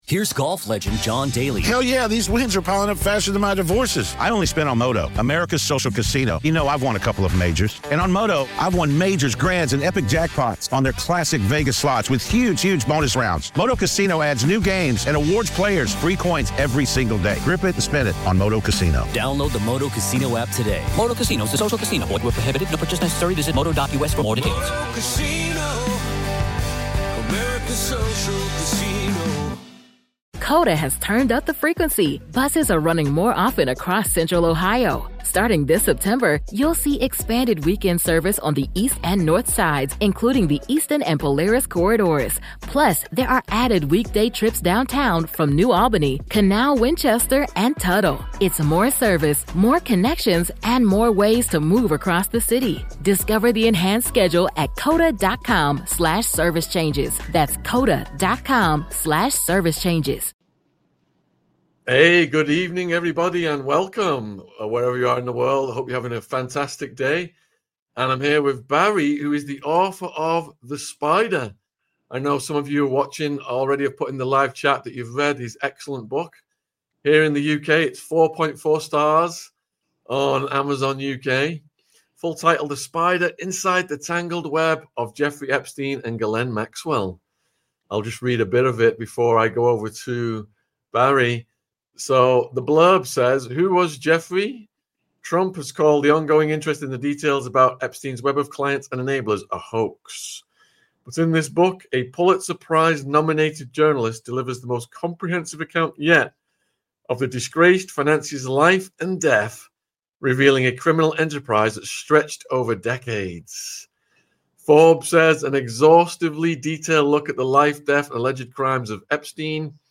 This in-depth conversation explores the crimes, the cover-ups, and the pursuit of justice — offering fresh insights into a case that continues to shock the world.